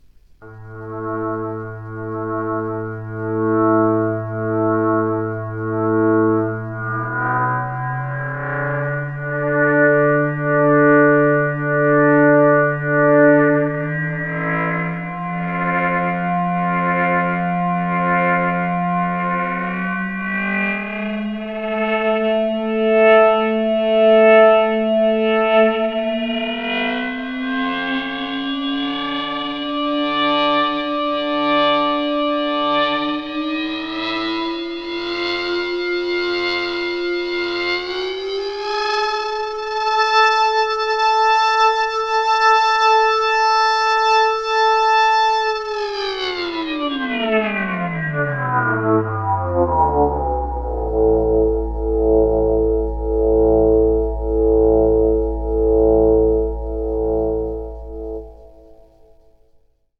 Ring modulation (i.e. 4 quadrant AM) can produce some crazy bass content!
Some D-Lev AM presets: